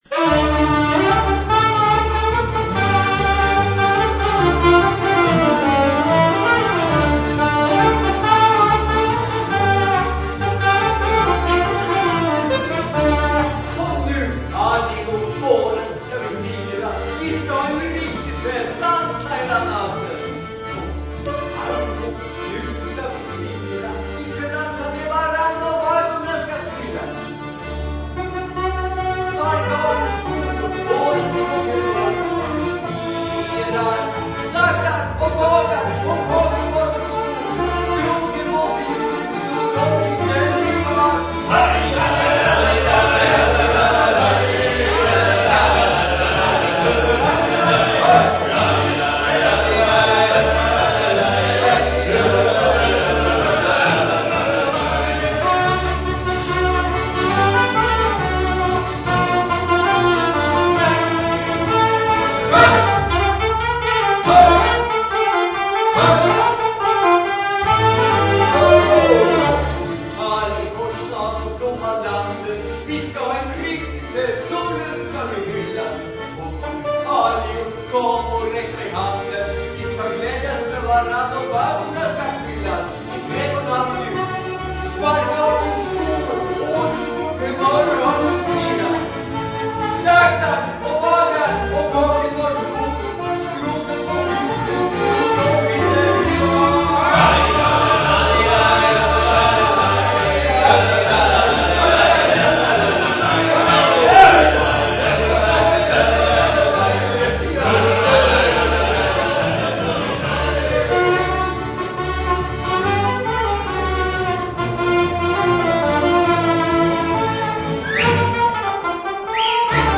Live Concert in Enköping 2011